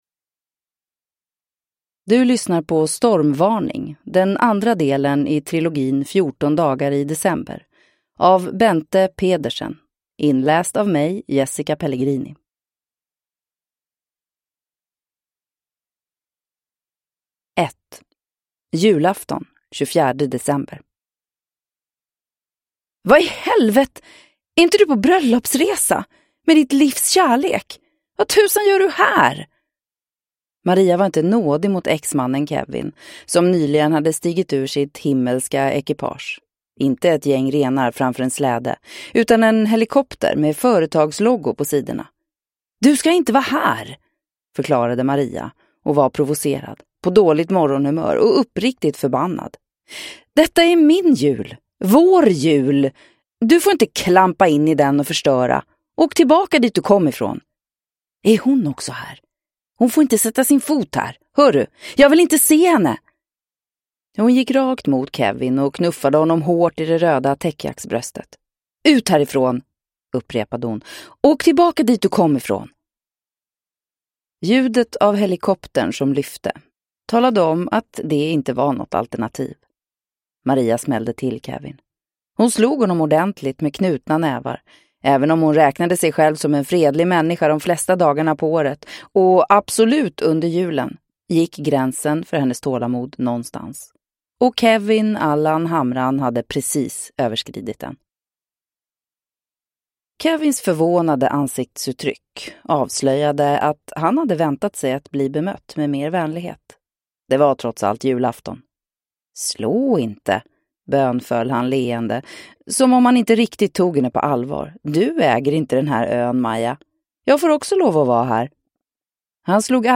Stormvarning (ljudbok) av Bente Pedersen